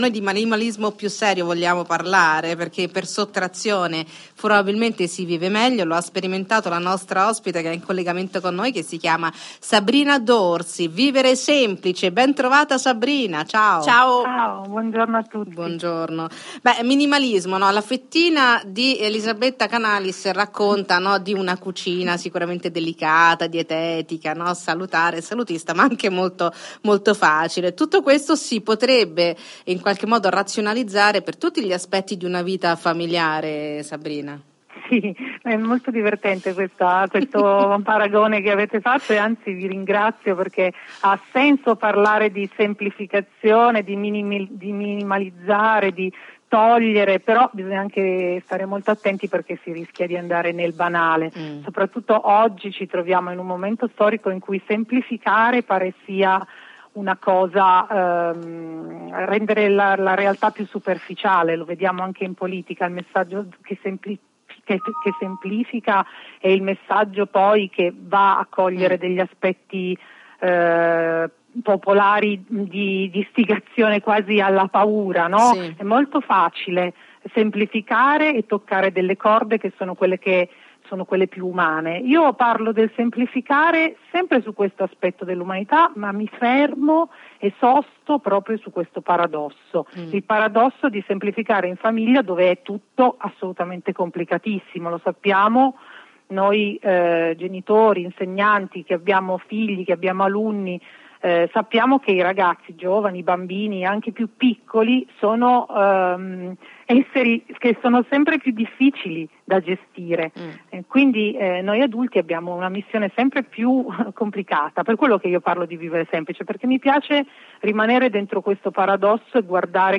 intervista_radio_cusano_campus.mp3